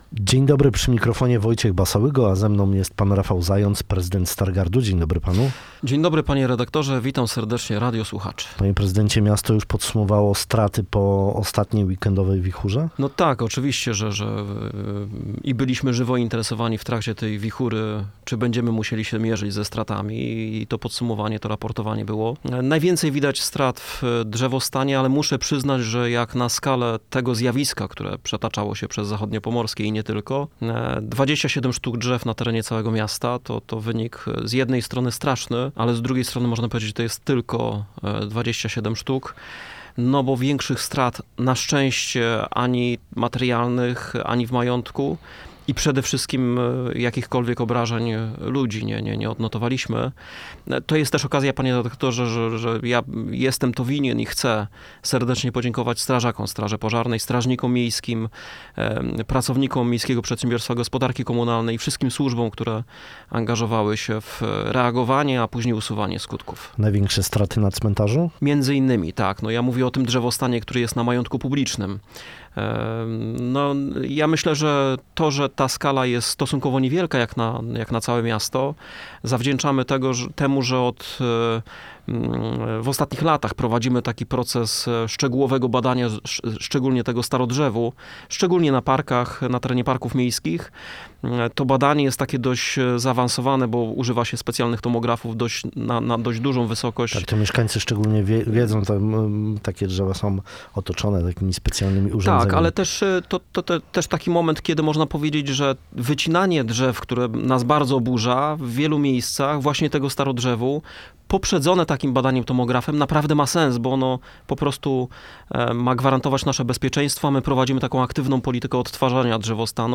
Rafał Zając, Prezydent Miasta Stargard powiedział nam, że najwięcej strat można było zobaczyć w drzewostanie – tylko, lub aż 27 drzew na terenie całego miasta.
Jest to jedna z wielu inwestycji, o których mówił nam nasz dzisiejszy Gość Rozmowy Dnia.